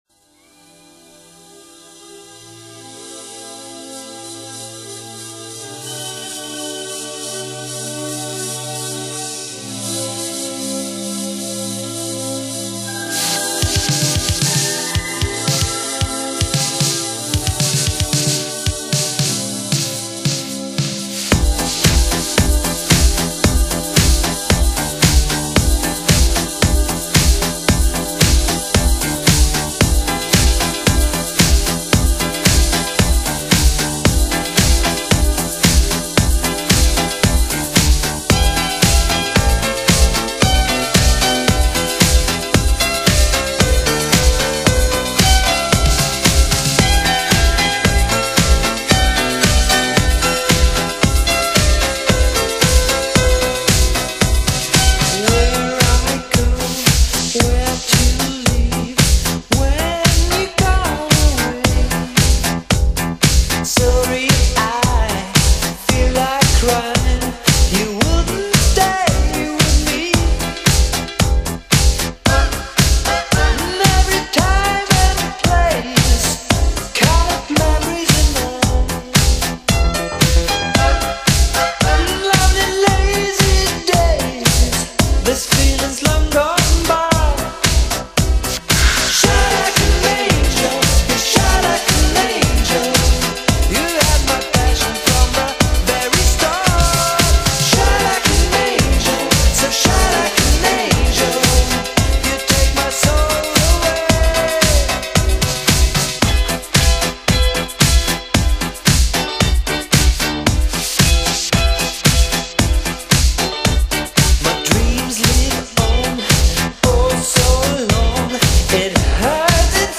Italo-Disco, Synth-pop 1985